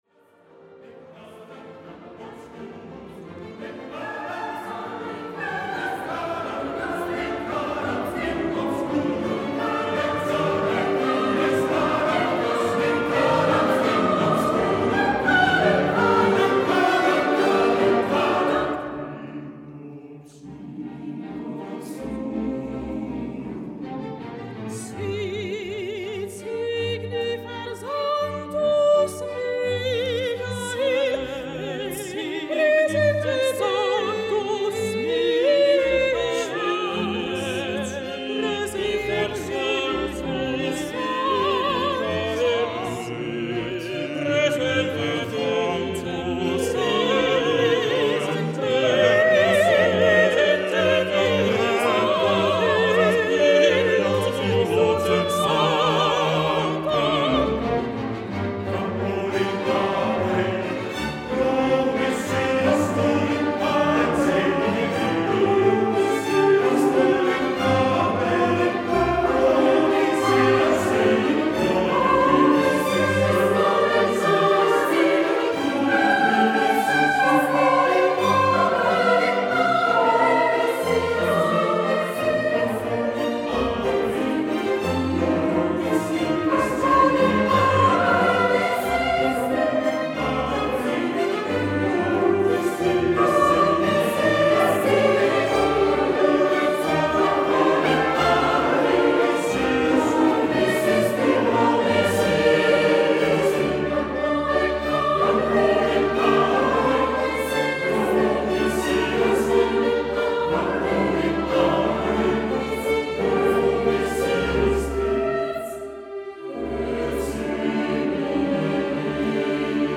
Ein grossartiges Werk mit Chor, Solisten & Orchester.